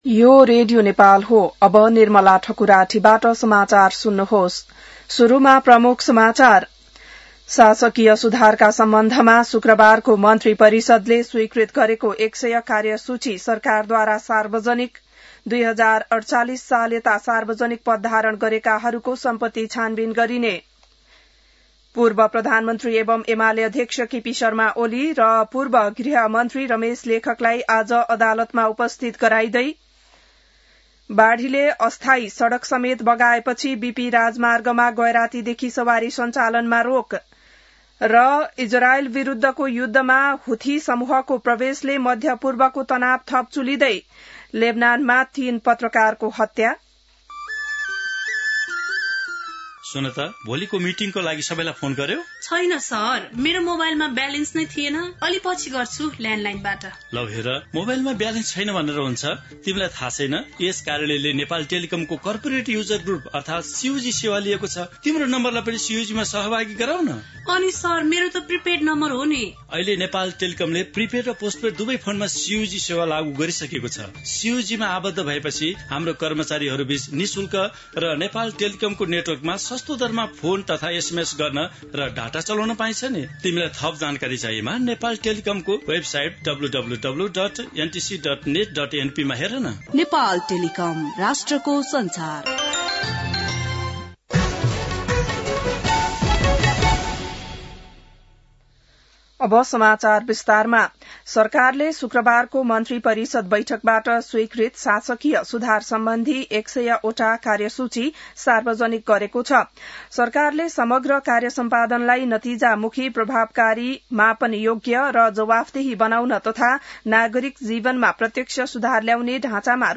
बिहान ७ बजेको नेपाली समाचार : १५ चैत , २०८२